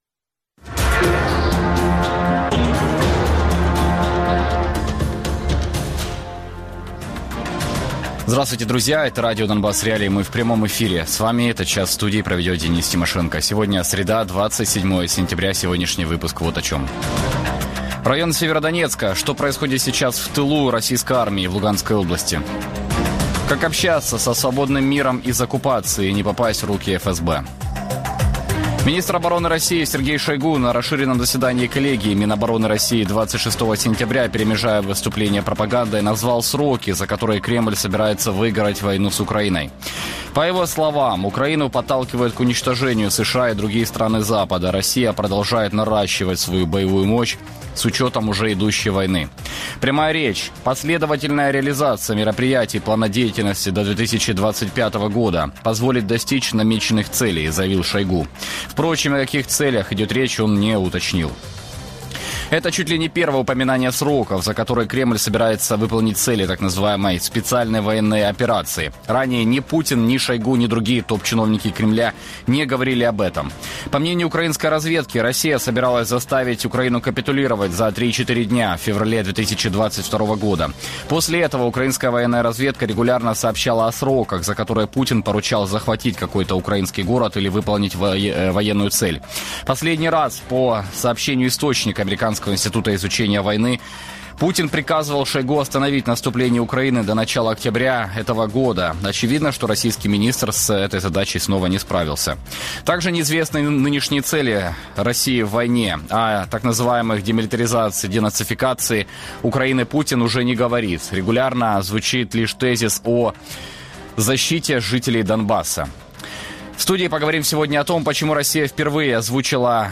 Гості: Роман Власенко – начальник Сєвєродонецької районної військової адміністрації